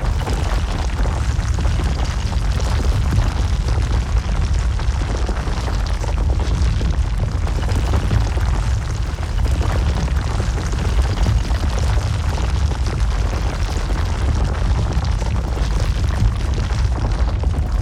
Rockfall_Loop_01.wav